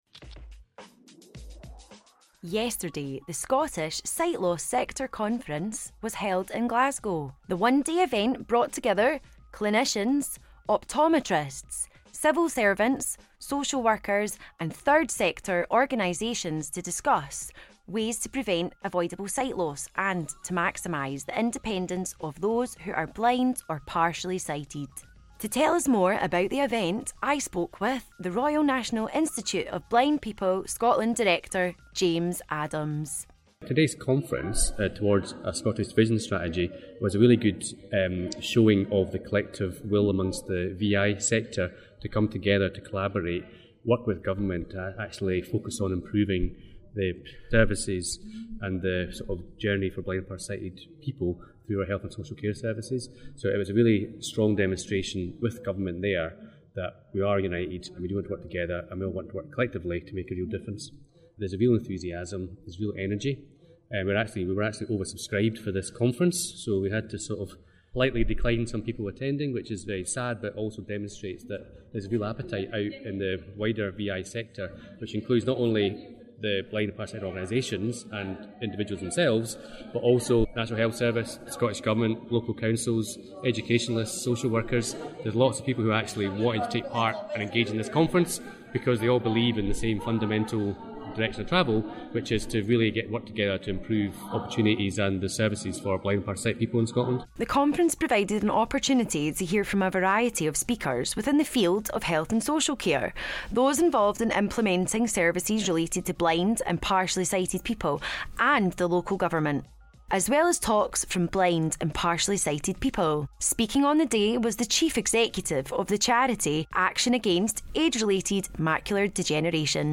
The Scottish Sight Loss Sector Conference, Towards a Scottish Vision Strategy, was held in Glasgow on Tuesday the 3rd of March.
was present on the day speaking with both contributors and attendees.